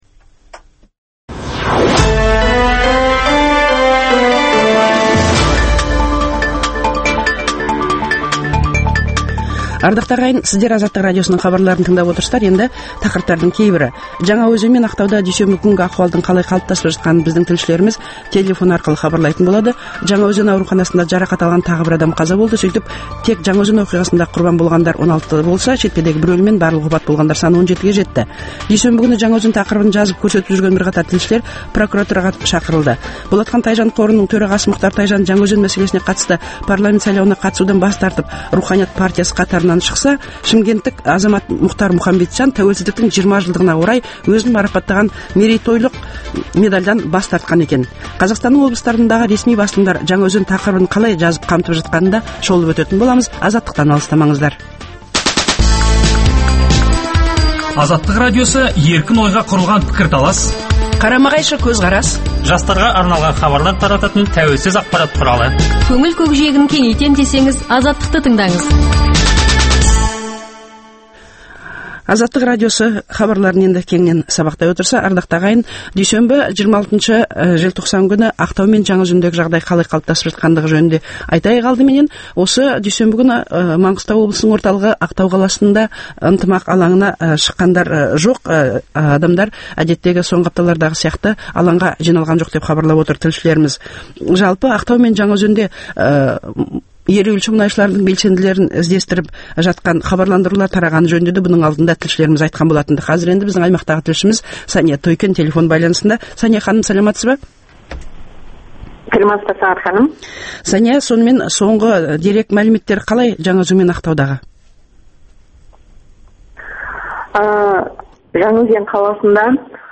Жаңаөзен мен Ақтауда дүйсенбі күнгі ахуалдың қалай қалыптасып жатқанын біздің тілшілеріміз телефон арқылы хабарлайтын болады. Жаңаөзен ауруханасында жарақат алған тағы бір адам қаза болды, сөйтіп тек Жаңаөзен оқиғасында құрбар болғандар саын 16-ға жетті, ал шетпедегі бір өліммен барлық опат болғандар - 17.